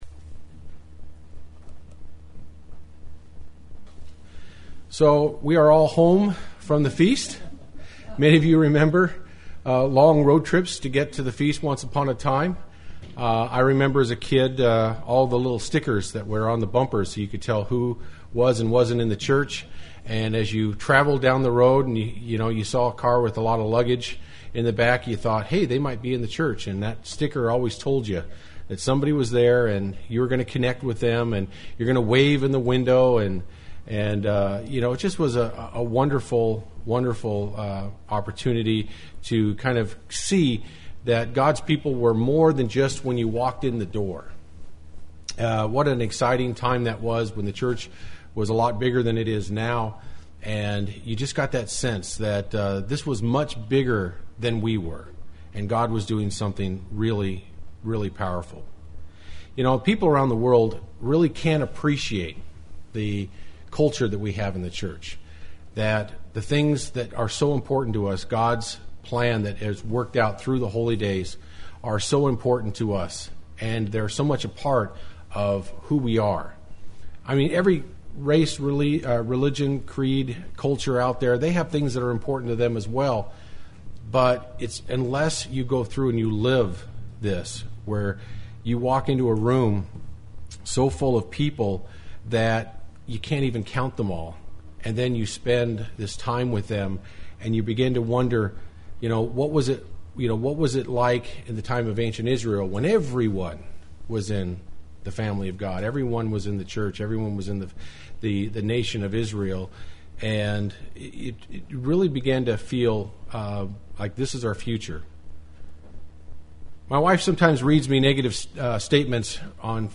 UCG Sermon Notes Here we are, back from the Feast.